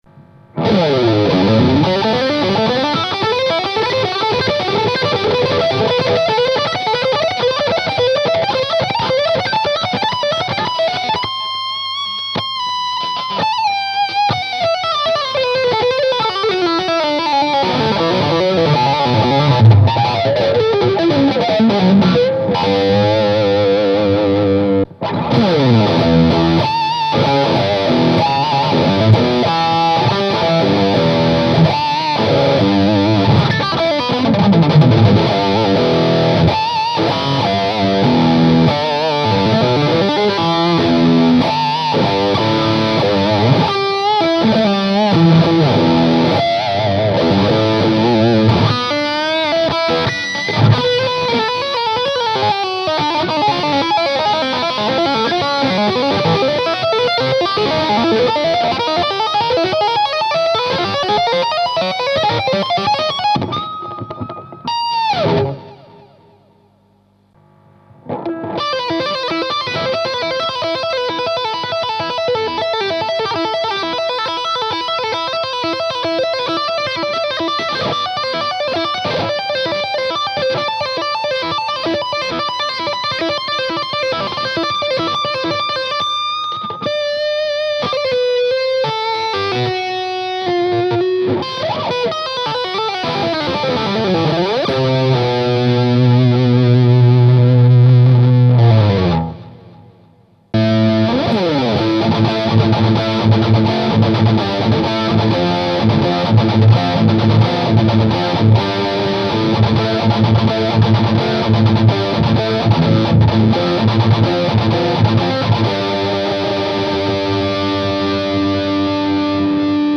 Voici une serie d'enregistrements qui ont été fait sur le Ten, l'ampli 10 watt de Pasqualiamps.
Le baffle utilisé est un "closed back" ave 2 HP Celestion G12-H. Les 3 premières plages ne contiennent aucun effet.
Plage 7 "Hard-Rock": Solo/Rythmique/Solo/Solo tapping/Rythmique "
Réglages Tactile Custom: Micro manche simple (splité) Tone 100 % - Volume 100 %
Réglage Pasqualiamps Ten: Tone 10 - Volume 1.5
Réglages des effets: Geoges Dennis (Gain: 10 Tone 0 Level 5)